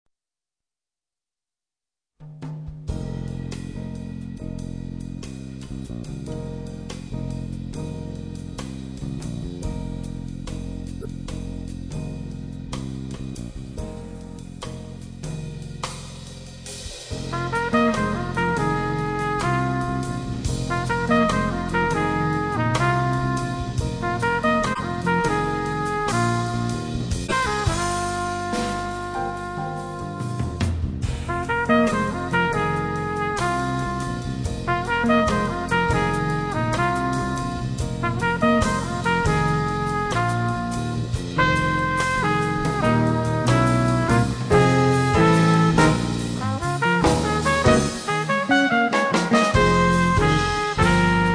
TROMBA E FLICORNO
CHITARRA
PIANO
BASSO